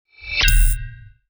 UI_SFX_Pack_61_18.wav